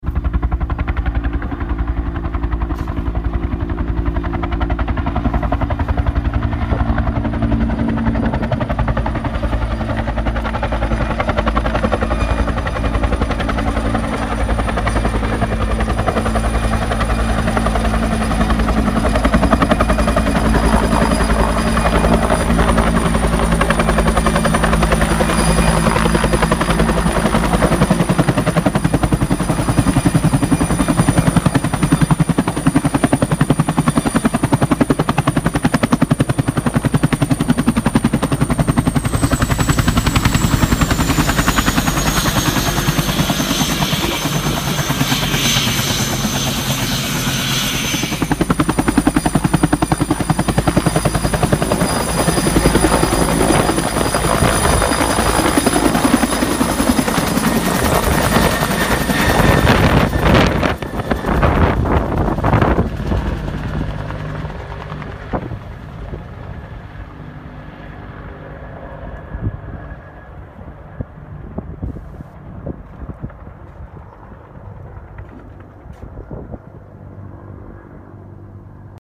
UH 1N Huey Landing and Takeoff sound effects free download
UH-1N Huey Landing and Takeoff during a rescue drill